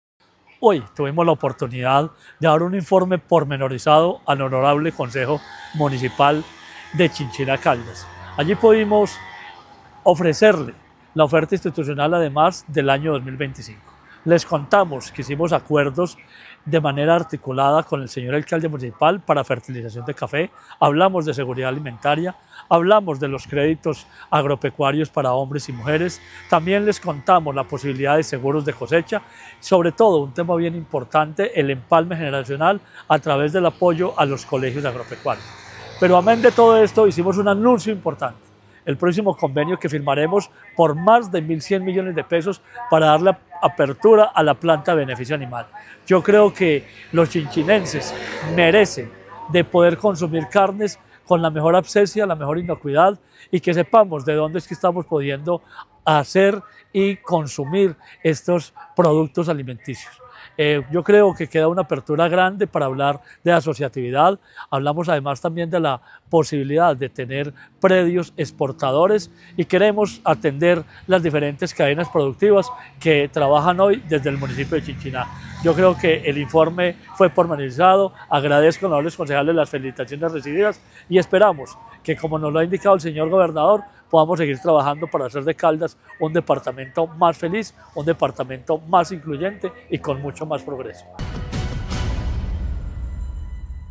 Un importante anuncio realizó el secretario de Agricultura y Desarrollo Rural de Caldas, Marino Murillo Franco, ante el Concejo Municipal de Chinchiná.
Marino Murillo Franco, secretario de Agricultura y Desarrollo Rural de Caldas.
Marino-Murillo-Franco-secretario-de-agricultura.mp3